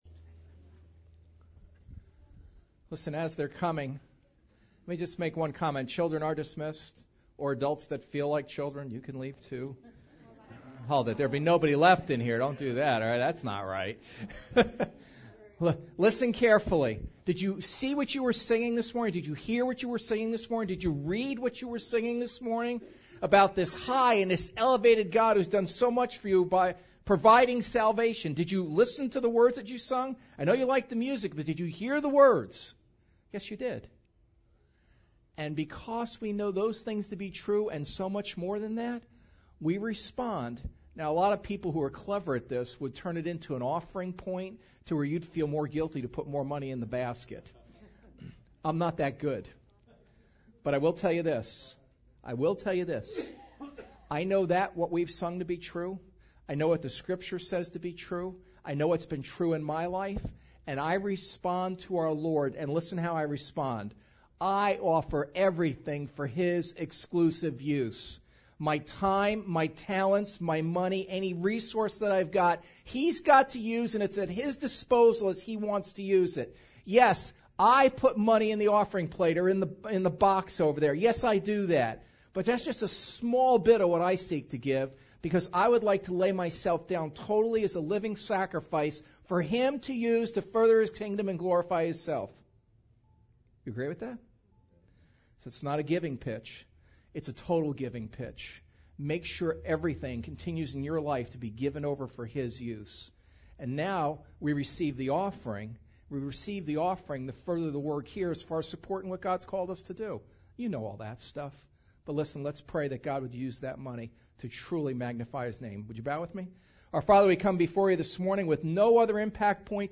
Psalms 24:1-6 Service Type: Sunday Service YOUR ‘CHECK ENGINE’ LIGHT IS ON!